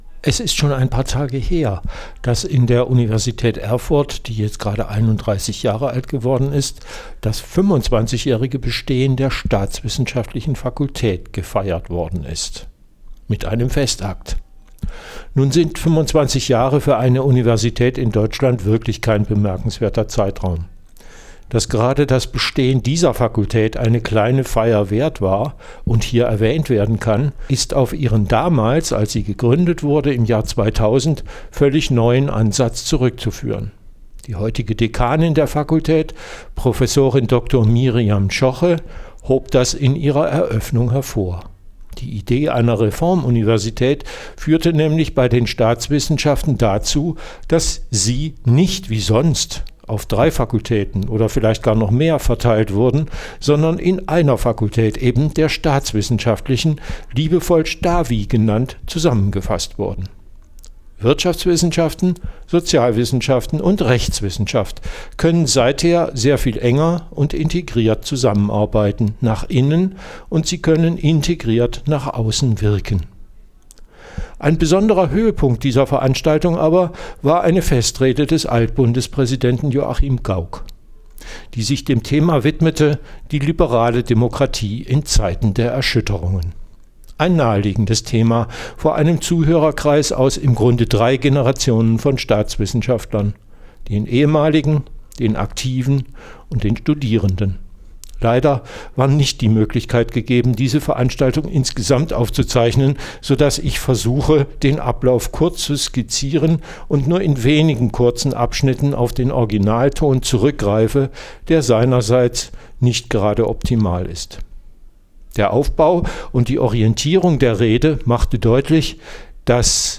Am 3.12.2025 fand an der Universität Erfurt ein Festakt aus Anlass des 25jährigen Bestehens der staatswissenschaftlichen Fakultät statt.